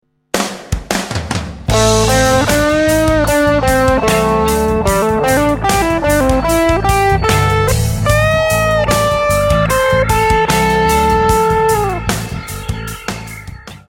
A bend is where the note is “bent” up in pitch by bending the string across the fretboard.
Sexy Solo Bends